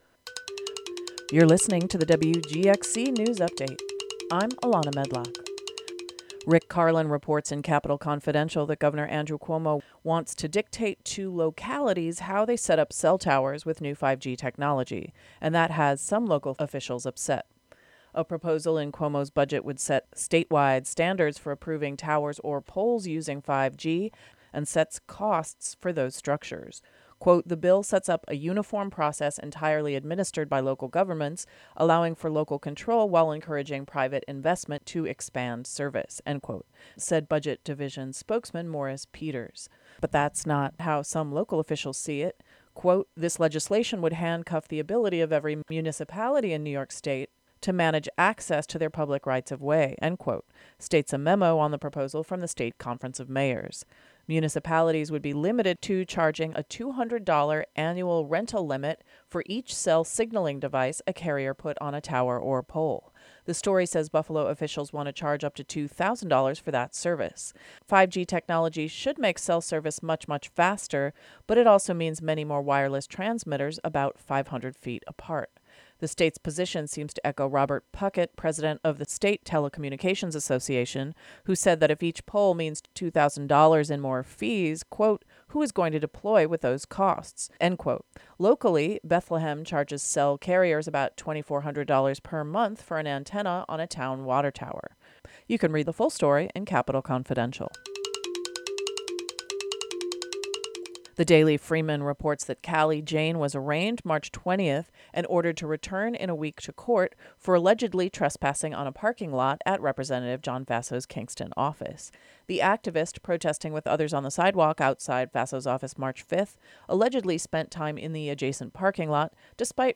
The local news update for the Hudson Valley.